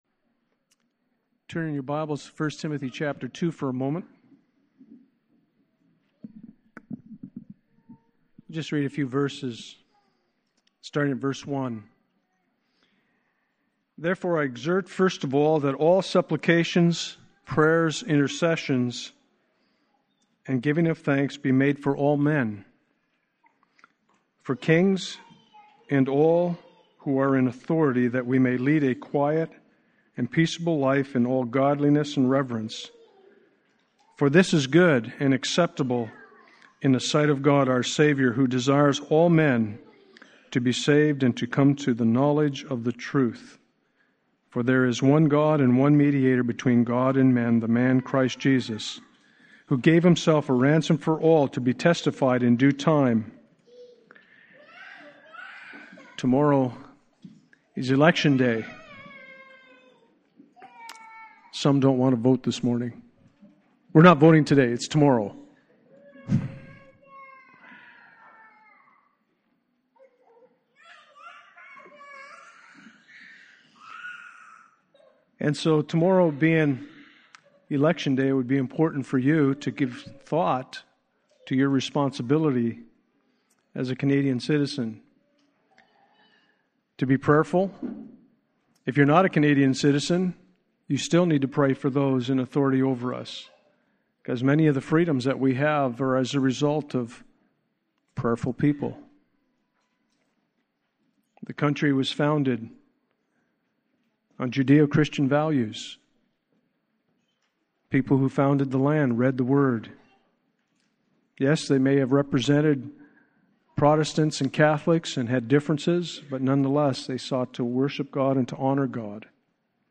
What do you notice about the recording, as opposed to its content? Passage: Philippians 1:1-30, Acts 16:11-24 Service Type: Sunday Morning